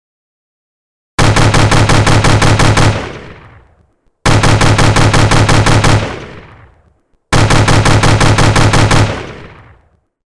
Âm thanh Tiếng Súng Bắn liên tục - Tải mp3 - M4r
Hiệu ứng âm thanh ghép video, edit video với âm thanh Tiếng Súng Bắn liên tục